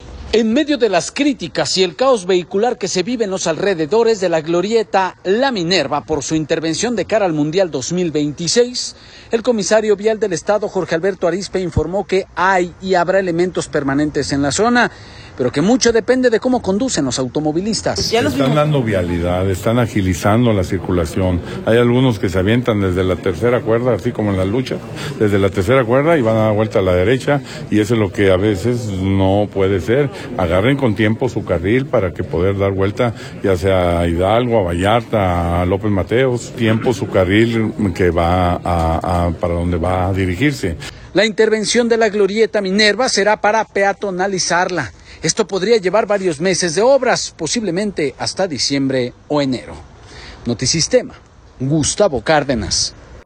En medio de las críticas y el caos vehicular que se vive en los alrededores de la glorieta La Minerva, por su intervención de cara al Mundial 2026. El comisario Vial del Estado, Jorge Alberto Arizpe, informó que hay y habrá elementos permanentes en la zona, pero que mucho depende de cómo conducen los automovilistas.